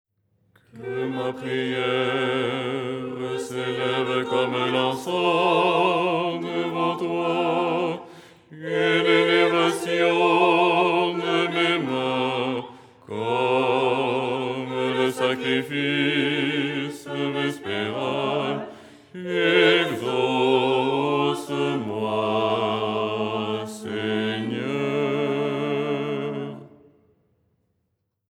Les tons byzantino-slaves en français
Basse
ton5-02-basse.mp3